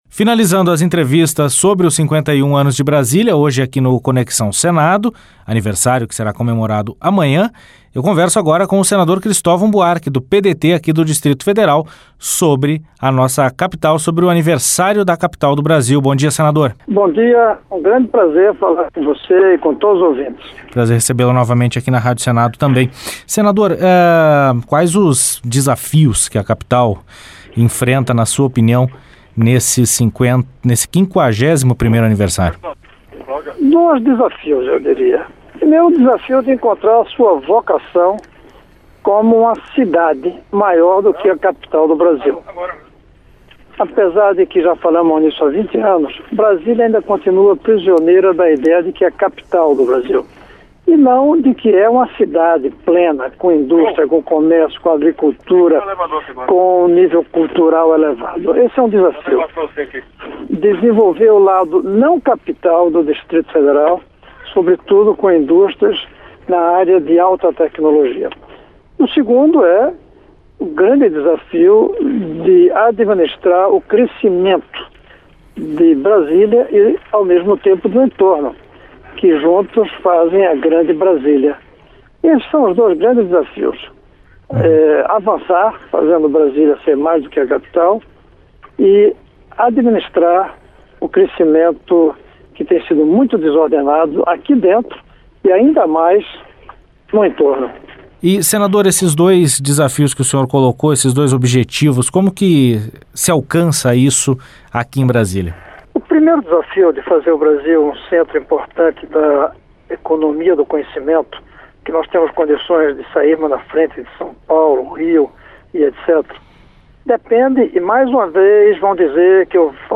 Entrevista com o senador Cristovam Buarque (PDT-DF).